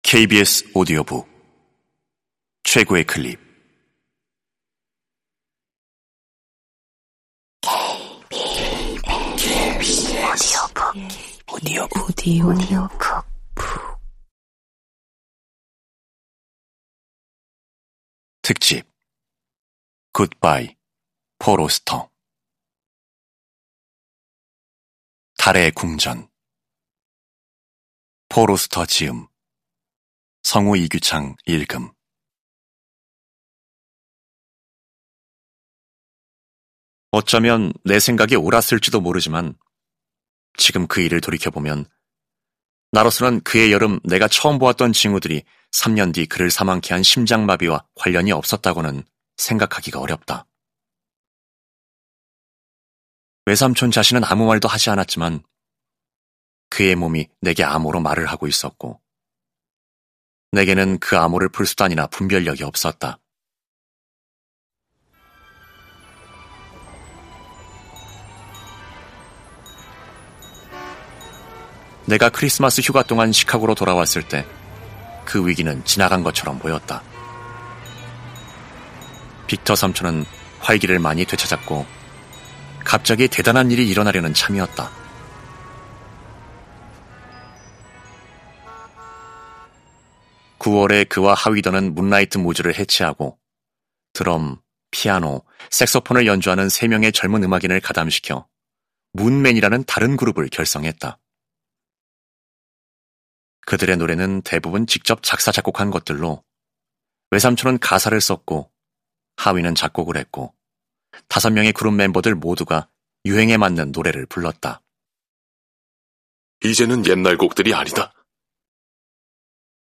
KBS 오디오북 - 최고의 클립